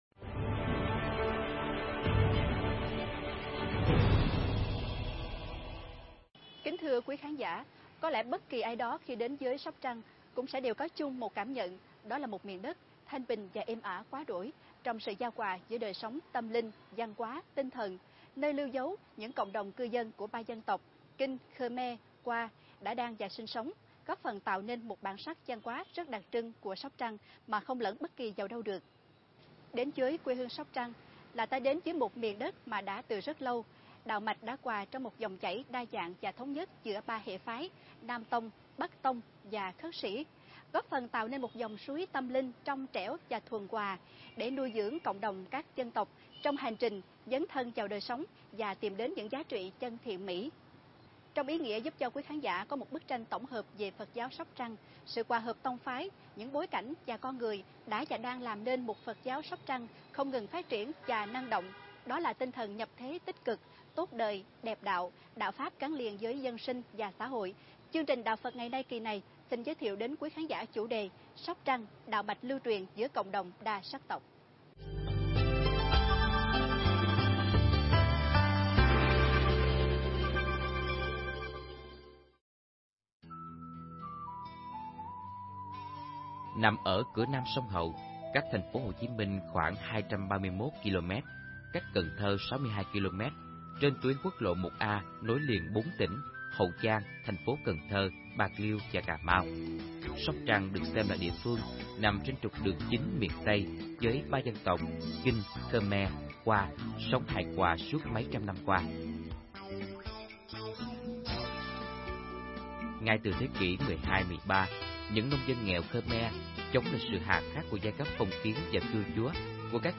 Mp3 Pháp âm Phật giáo Sóc Trăng